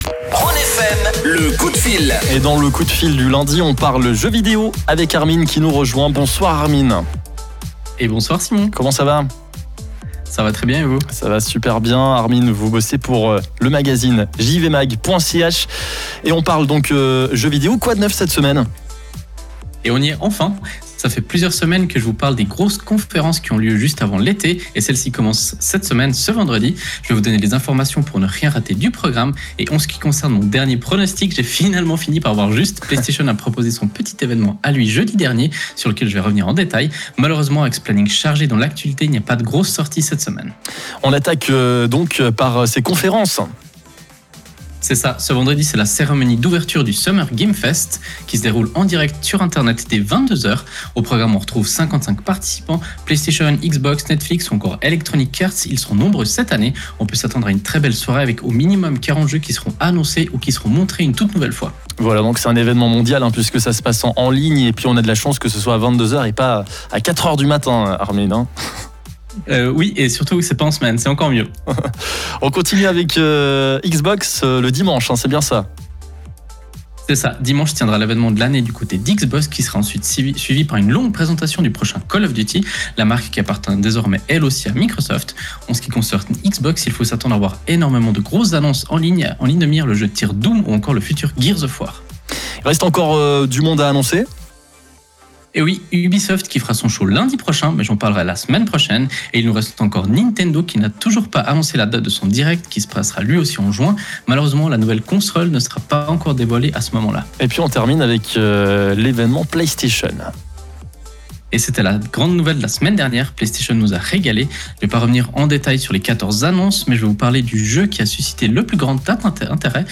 Comme tous les lundis, nous avons la chance de présenter notre chronique gaming sur la radio Rhône FM. Une capsule gaming qui retrace l’actualité du moment.
Vous pouvez réécouter le direct Rhône FM via le flux qui se trouve juste en haut de l’article.